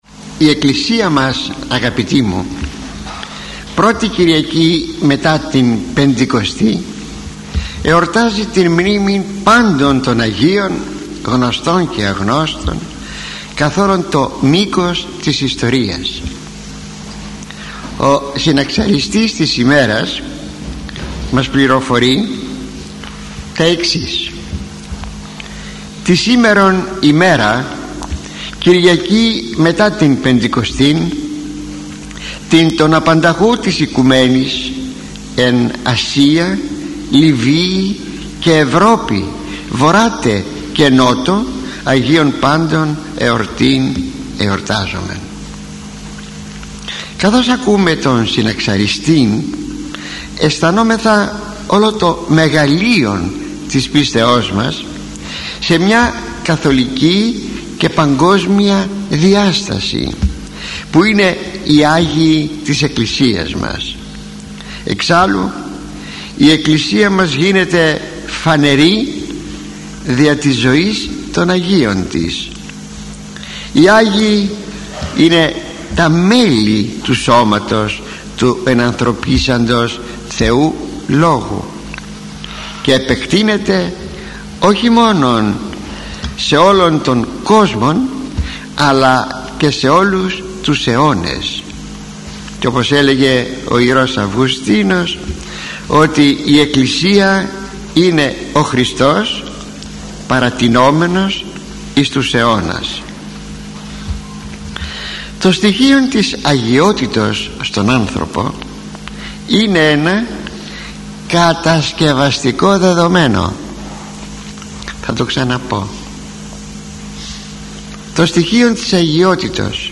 Ο λόγος του ήταν πάντοτε μεστός, προσεγμένος, επιστημονικός αλλά συνάμα κατανοητός και προσιτός, ακόμη και για τους πλέον απλοϊκούς ακροατές του.